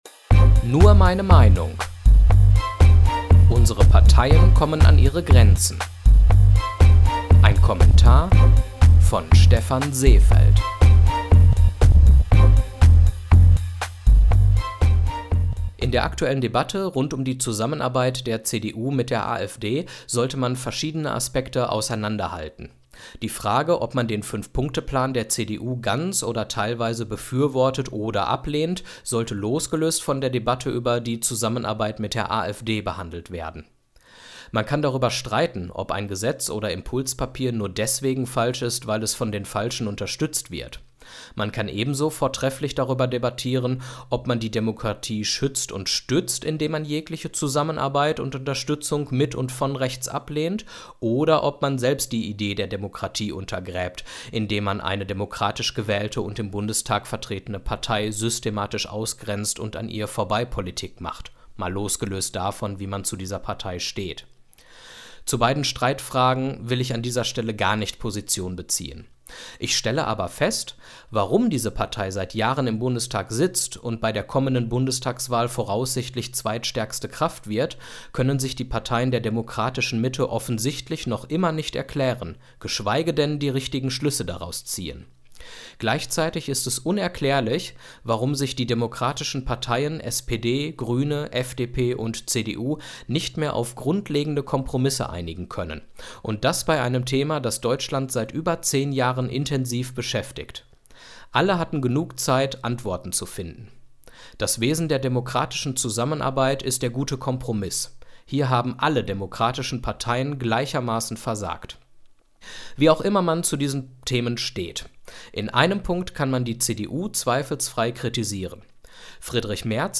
Mit meinen Kommentaren möchte ich meine Gedanken mit euch teilen, zum Nachdenken und zur Diskussion anregen sowie einfach mal zeigen, was einen jungen Mann wie mich so alles beschäftigt – nur (m)eine Meinung!
News Talk